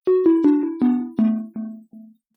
Video Game - Die or Lose Life
Category 🎮 Gaming
die digital game life lose synth video sound effect free sound royalty free Gaming